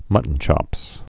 (mŭtn-chŏps)